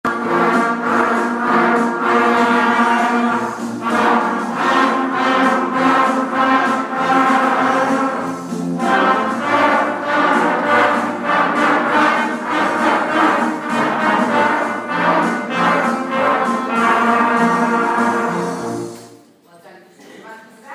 trumpets 2